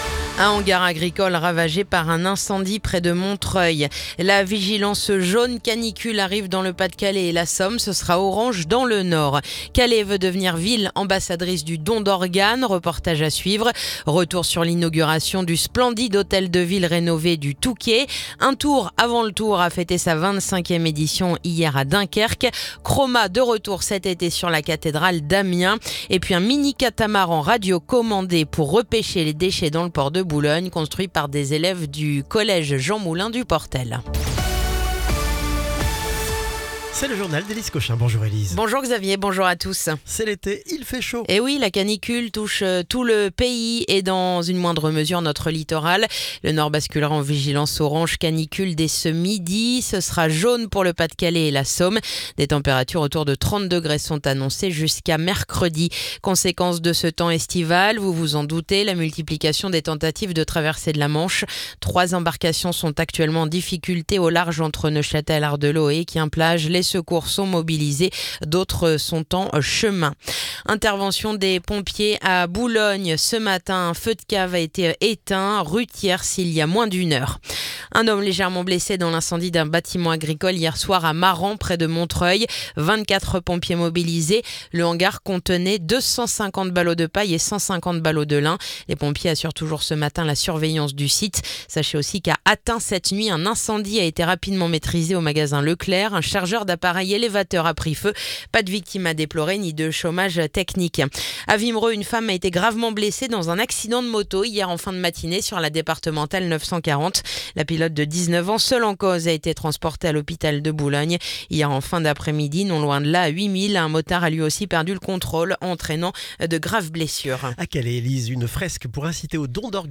Le journal du lundi 30 juin